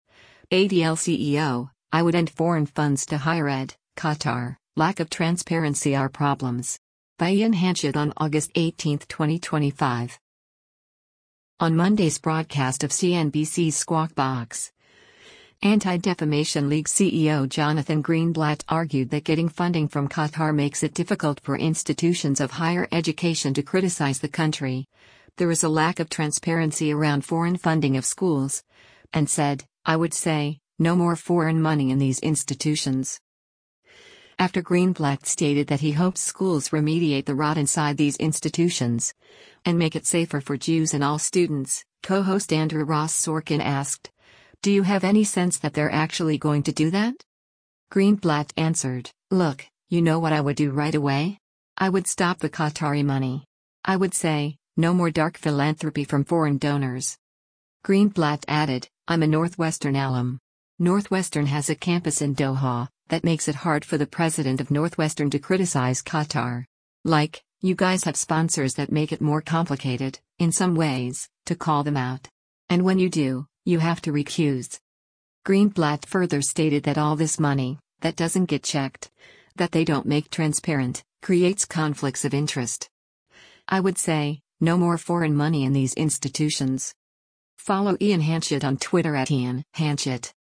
On Monday’s broadcast of CNBC’s “Squawk Box,” Anti-Defamation League CEO Jonathan Greenblatt argued that getting funding from Qatar makes it difficult for institutions of higher education to criticize the country, there is a lack of transparency around foreign funding of schools, and said, “I would say, no more foreign money in these institutions.”
After Greenblatt stated that he hopes schools “remediate the rot inside these institutions, and make it safer for Jews and all students”, co-host Andrew Ross Sorkin asked, “Do you have any sense that they’re actually going to do that?”